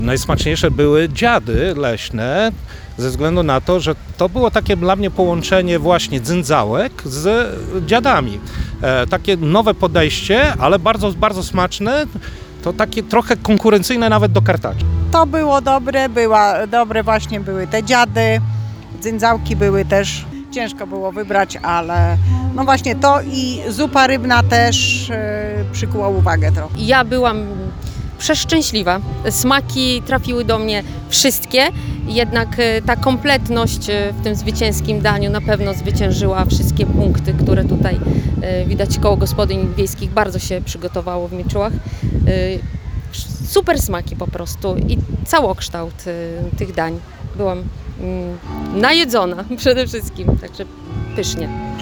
Gołdap Regionalne jadło, konkurs ekologiczny i tysiące gości – tak wyglądała sobota na Placu Zwycięstwa w Gołdapi na XII Eko-Pikniku.
Starosta Powiatu Gołdap Krzysztof Kazaniecki, organizator pikniku, podkreślał wartości, które dzięki tej imprezie zaszczepia się najmłodszym mieszkańcom.